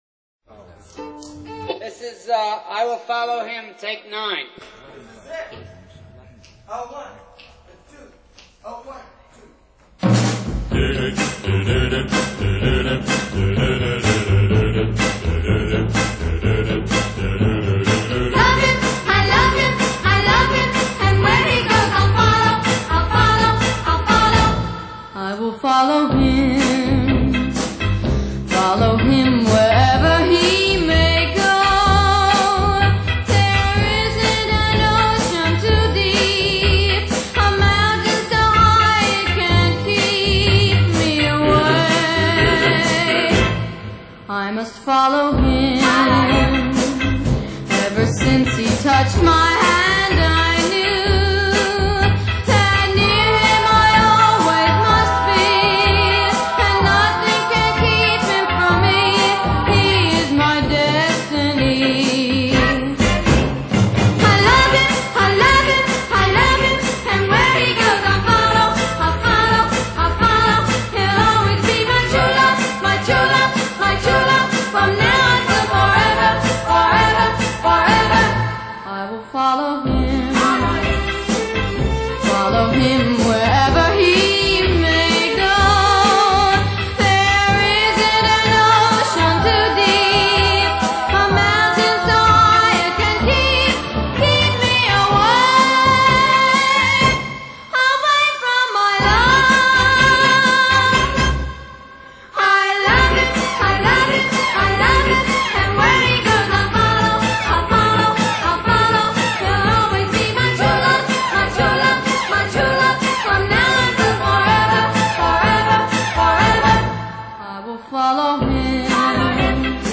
Genre: 60's pop, oldies, vocal